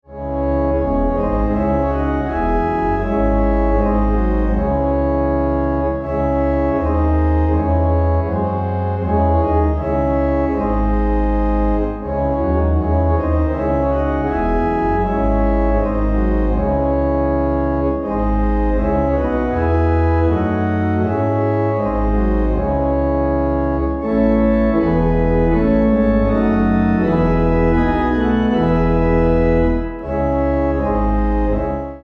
C#m